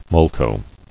[mol·to]